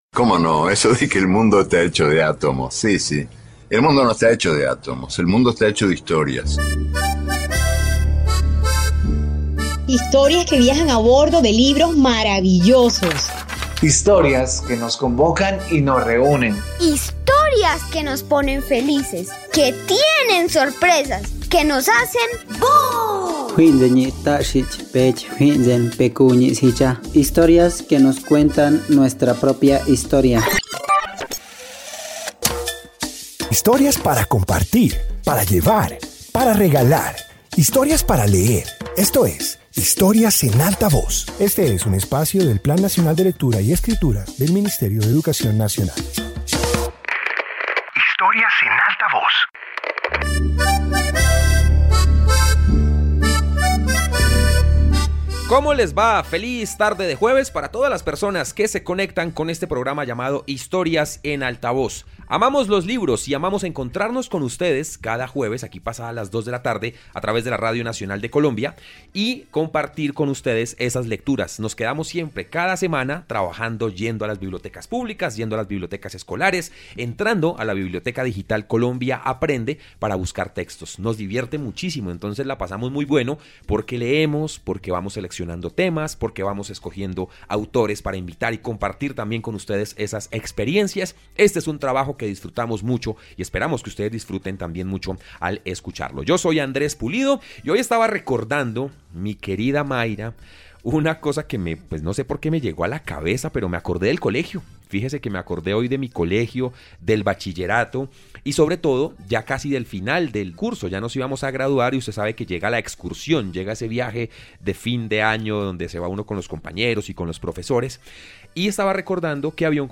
Introducción Este episodio de radio presenta historias sobre personajes que cuestionan, imaginan y transforman su entorno. Incluye relatos que reflejan creatividad, inconformismo y la búsqueda de nuevas posibilidades.